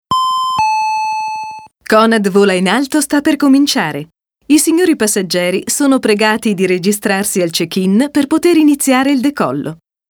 Sprecherin italienisch. Attrice, doppiatrice, voice-over e speaker pubblicitaria
Sprechprobe: Werbung (Muttersprache):
female voice over artist italian. Attrice, doppiatrice, voice-over e speaker pubblicitaria